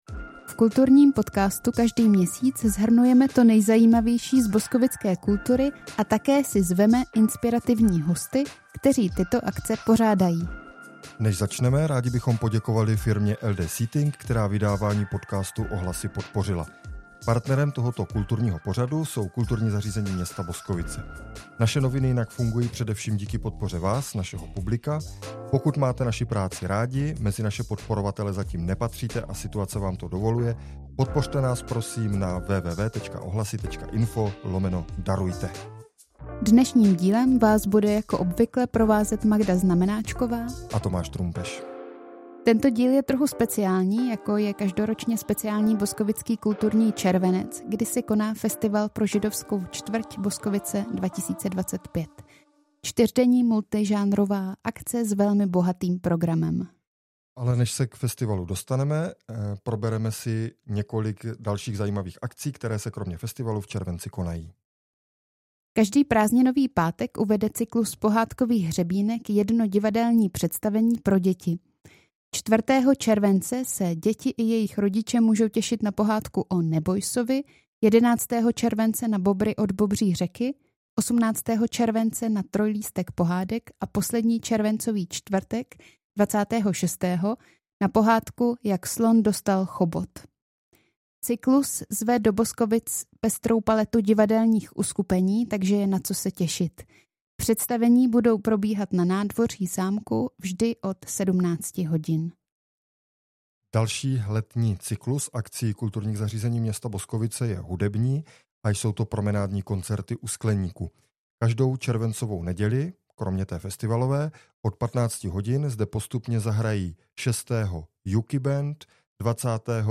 Namísto obvyklého rozhovoru jsme tentokrát natočili festivalovou anketu, kde se vám postupně devět lidí, kteří se okolo kultury točí, svěří s tím, na co se z festivalu Boskovice 2025 nejvíc těší a jak ho vnímají. Součástí podcastu jsou i pozvánky na vybrané další červencové akce, ty si zde můžete také přečíst.